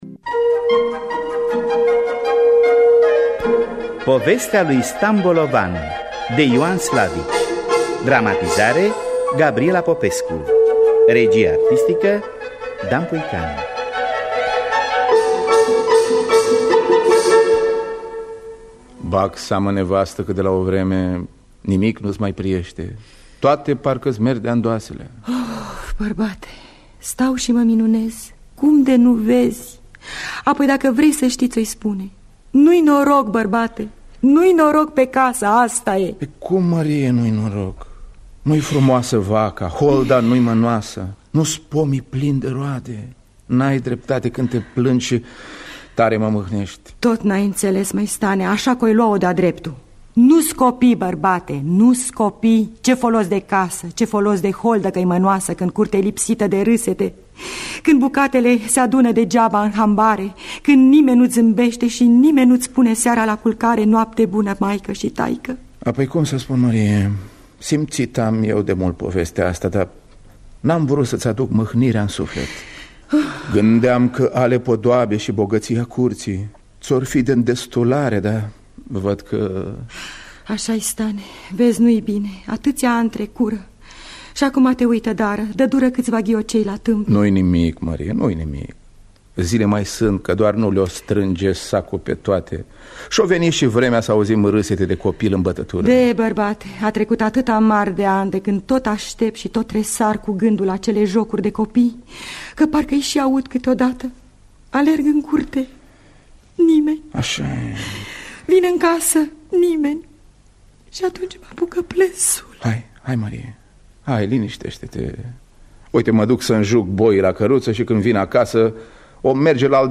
Povestea lui Stan Bolovan basm de Ioan Slavici.mp3